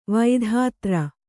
♪ vaidhātra